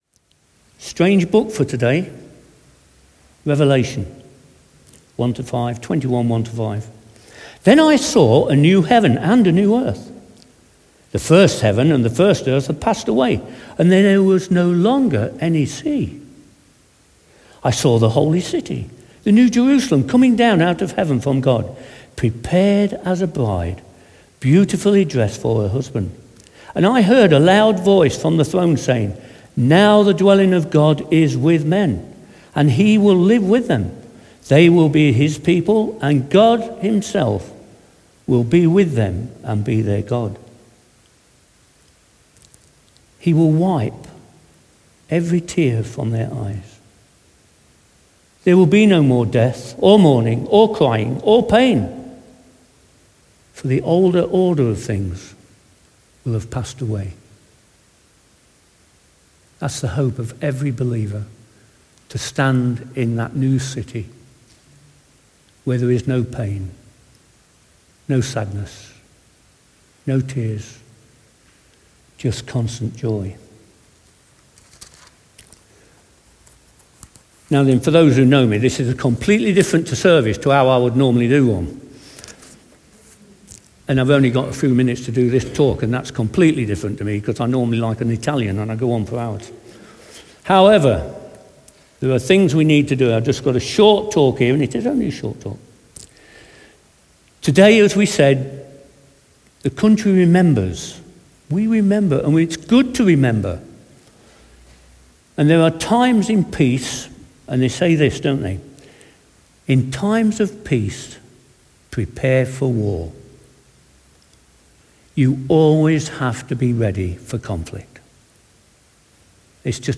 talk on Remembrance Sunday 2023